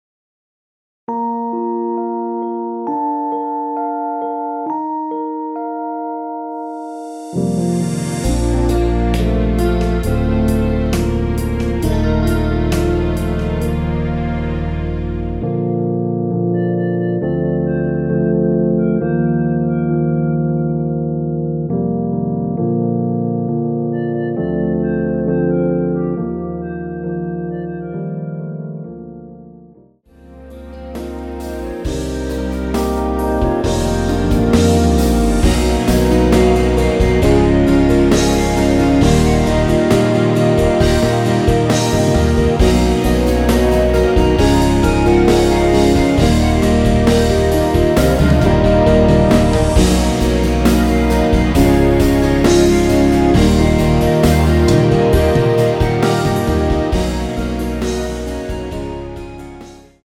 원키에서(-4)내린 멜로디 포함된 MR입니다.
Bb
멜로디 MR이라고 합니다.
앞부분30초, 뒷부분30초씩 편집해서 올려 드리고 있습니다.
중간에 음이 끈어지고 다시 나오는 이유는